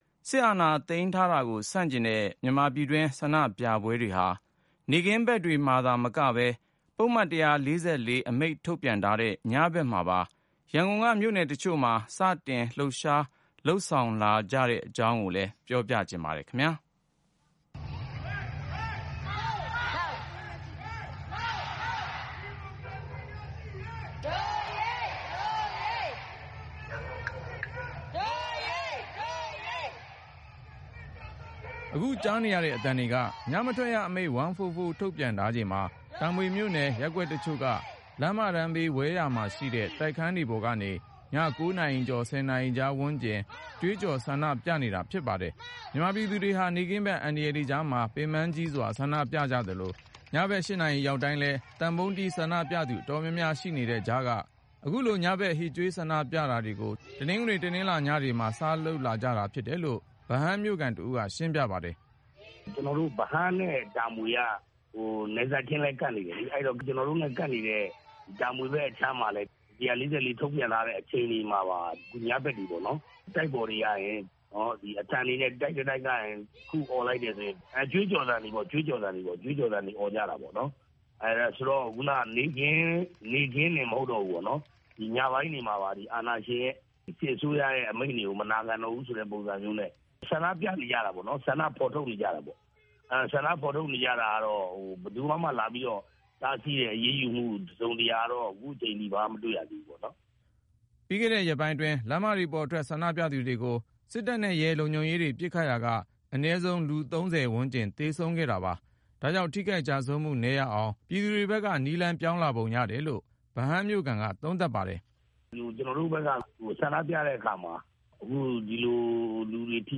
အခုကွားနရေတဲ့ အသံတှကေ ညမထှကျရအမိန့ျ 144 ထုတျထားခြိနျမှာ တာမှမွေို့နယျ ရပျကှကျတခြို့က လမျးမတနျးဘေး ဝဲယာမှာရှိတဲ့ တိုကျခနျးတှပေေါျကနေ ည ၉ နာရီကြောျ ၁၀ နာရီကွားဝနျးကငြျ ကွှေးကွောျ ဆန်ဒပွနကွေတာဖွဈပါတယျ။